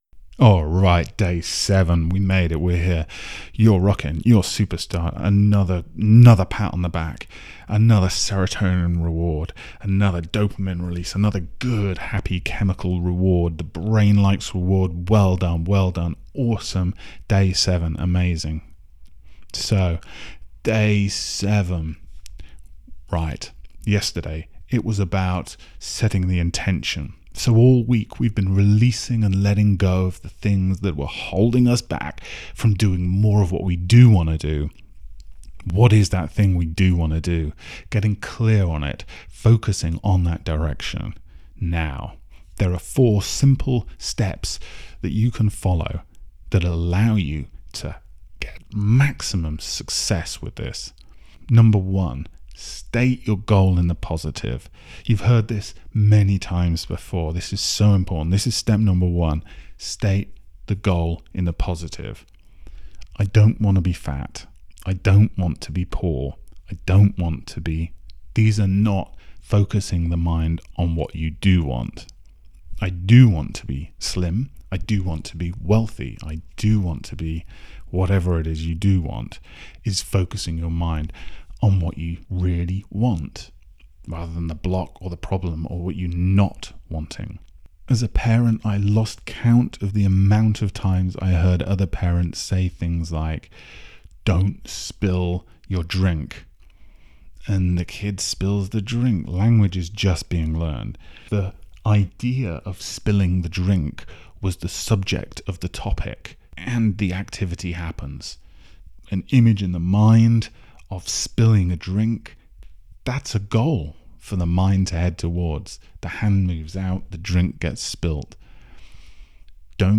Its best to slip on a pair of headphones... get yourself comfortable, press play and relax with this 11 minute Hypnotic PowerNap for Releasing & Letting Go